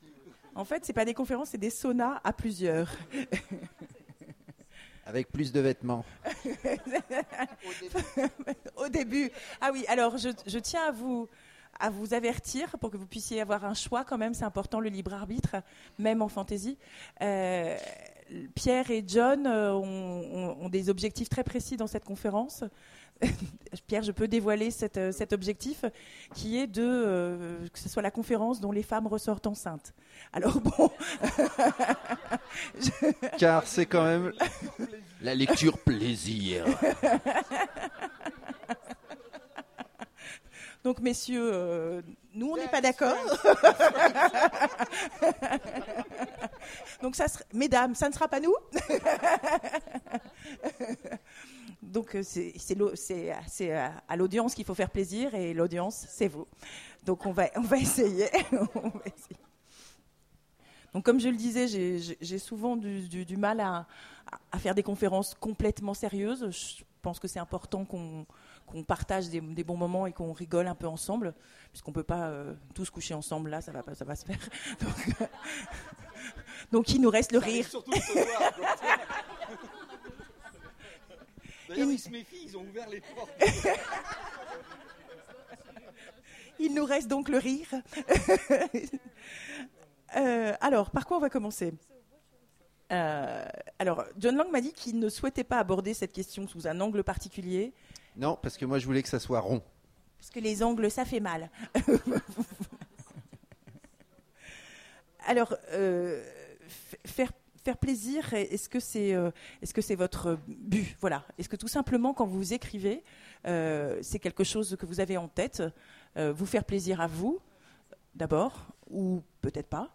Imaginales 2015 : Conférence La fantasy...